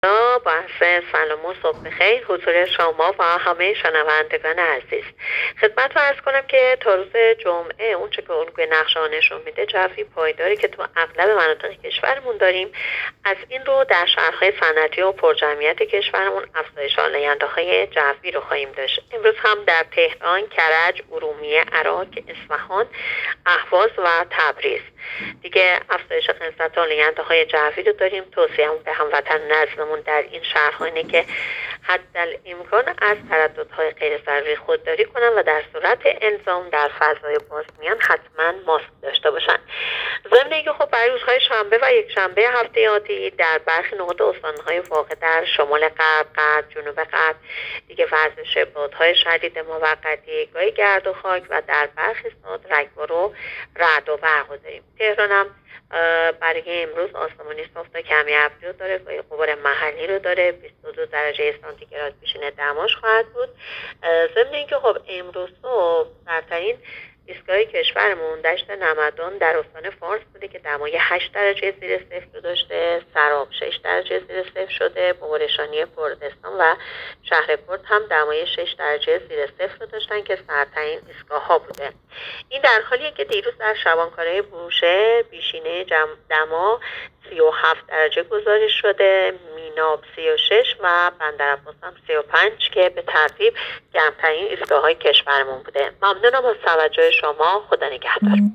گزارش رادیو اینترنتی پایگاه‌ خبری از آخرین وضعیت آب‌وهوای ۲۱ آبان؛